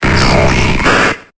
Cri de Spectrum dans Pokémon Épée et Bouclier.